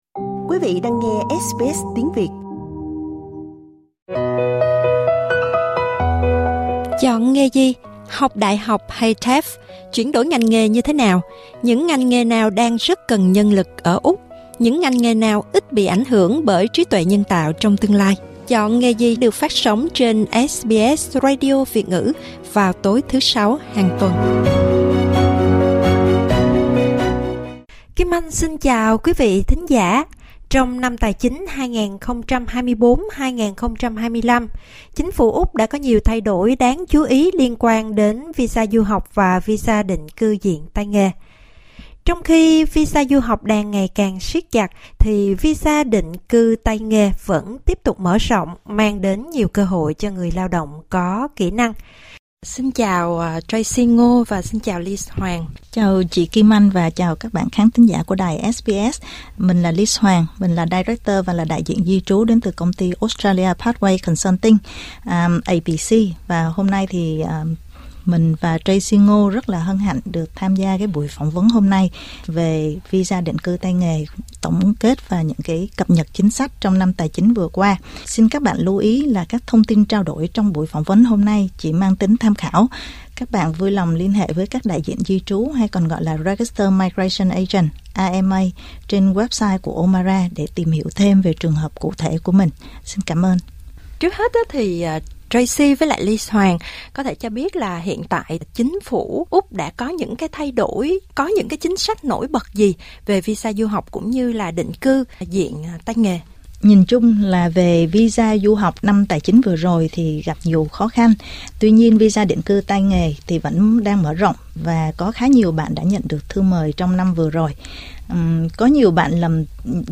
tại phòng thu SBS (SBS Vietnamese)